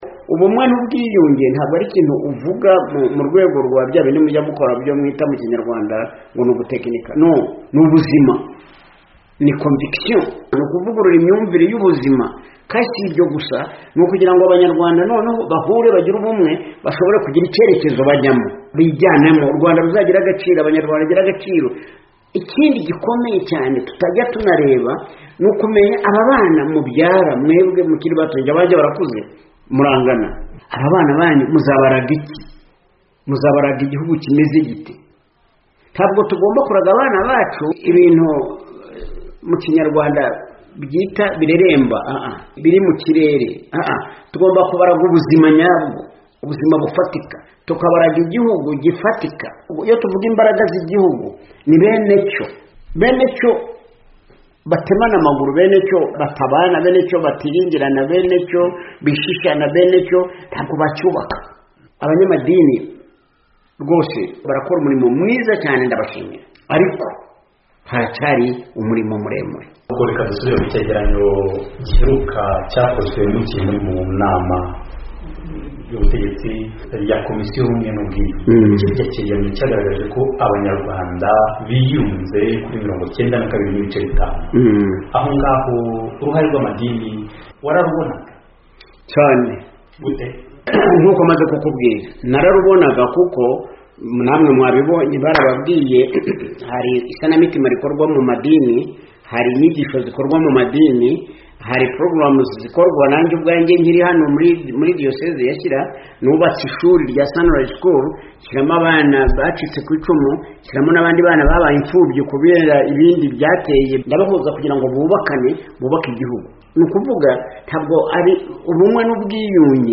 Urugendo rw'Ubwiyunge mu Rwanda Rugeze He? Ikiganiro na Musenyeri John Rucyahana